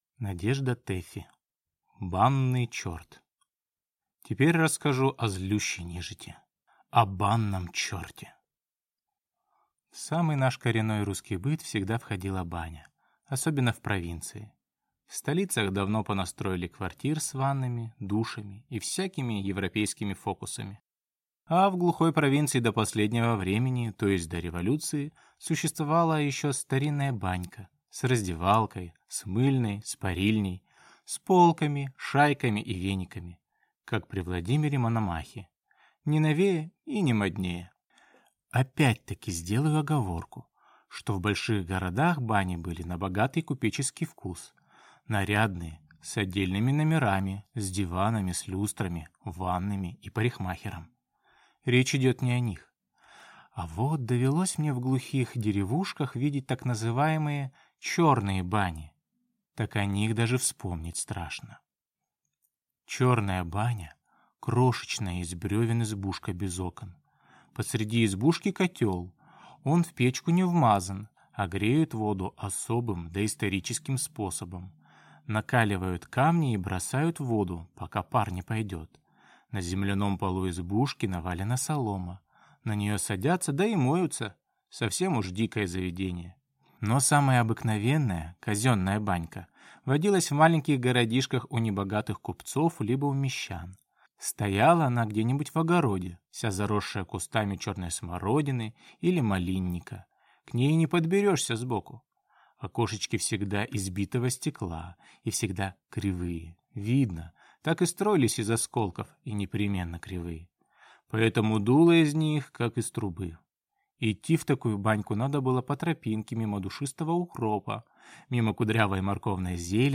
Аудиокнига Банный черт | Библиотека аудиокниг